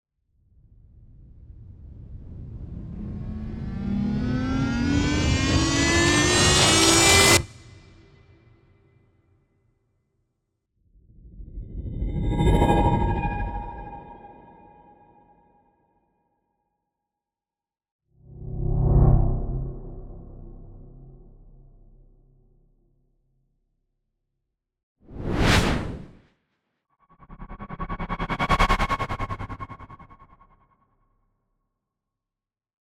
Whoosh
A great tool for sound designers or producers need transition effects.
Falcon-Whoosh.mp3